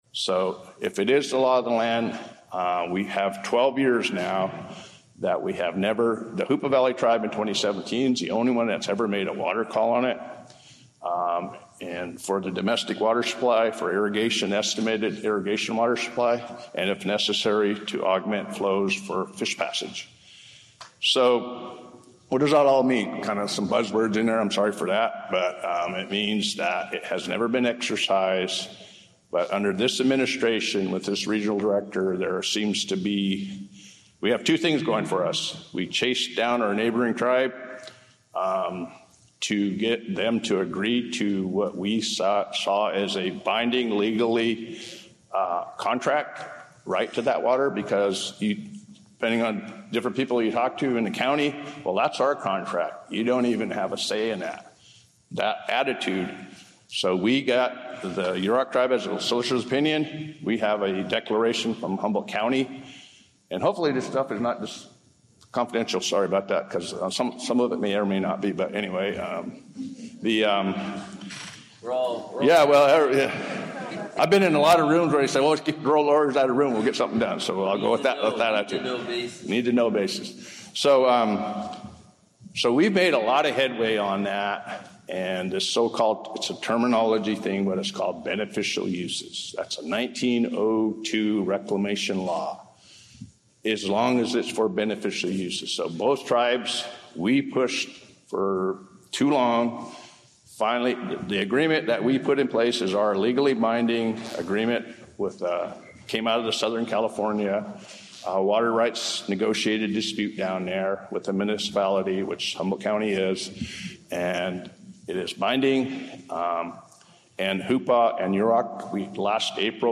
General Meeting 2-21-26 Hr6